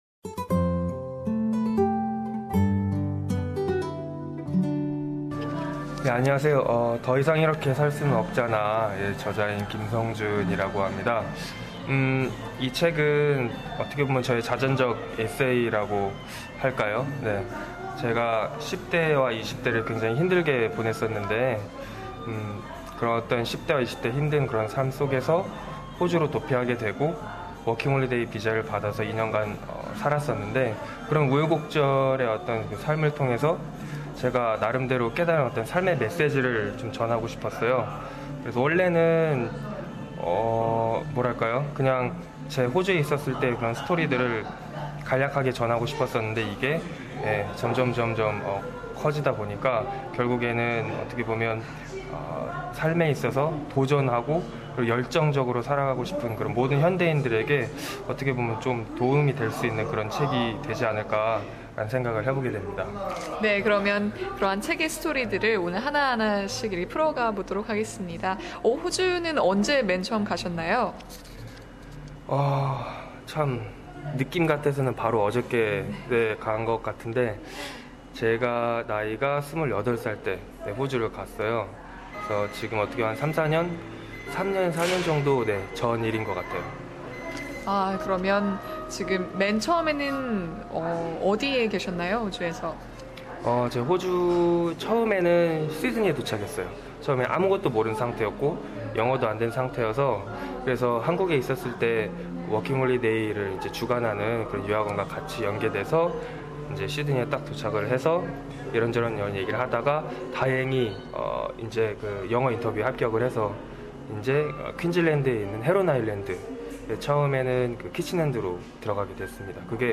Interview series with Korean working holiday makers